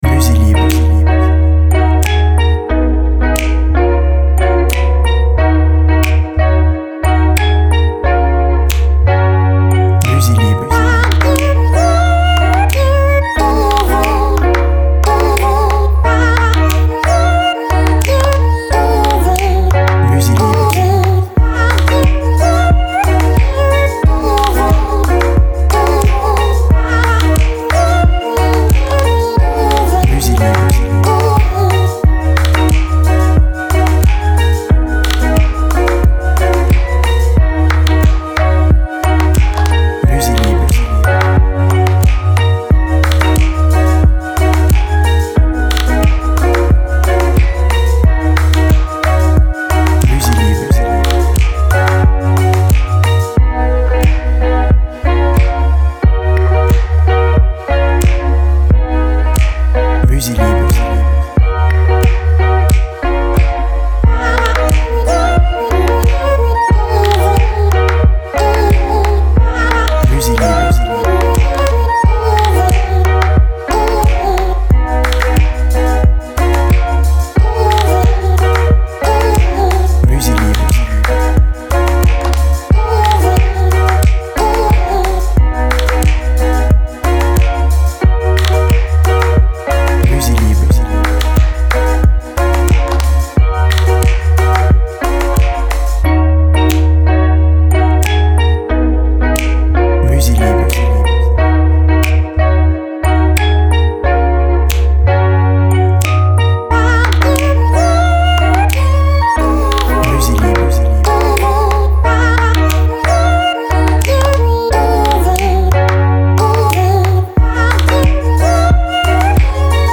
BPM Lent